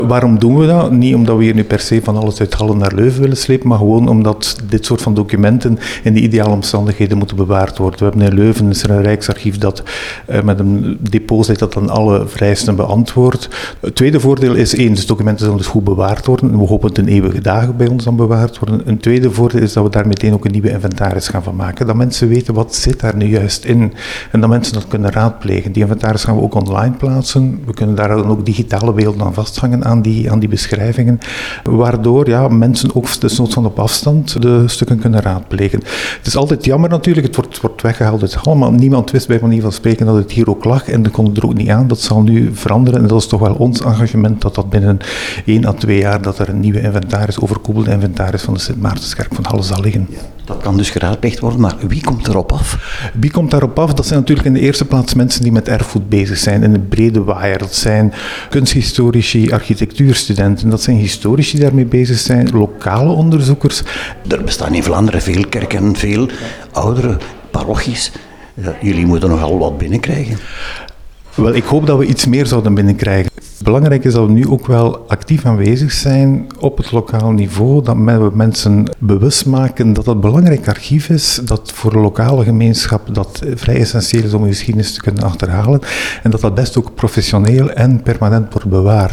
Op de lokale radio